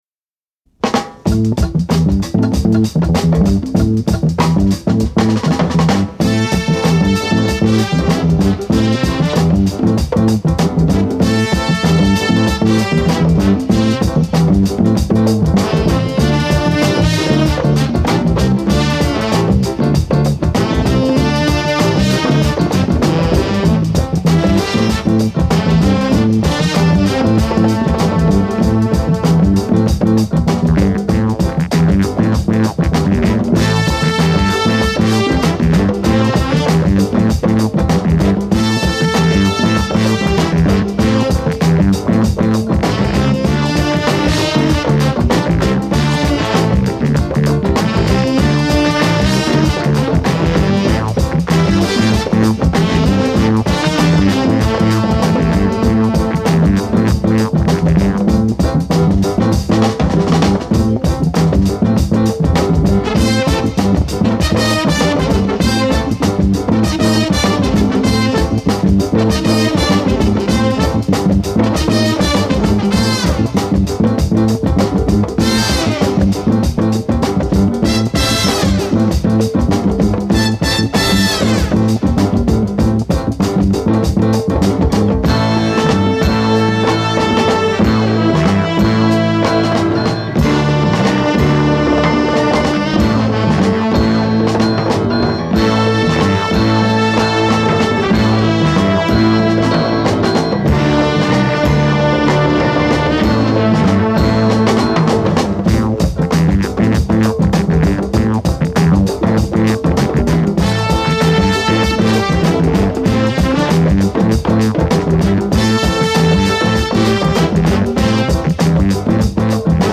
break-loaded instrumental funk album
heavy horns and killer ivories
squelchy moog at the groove’s helm
deep African funk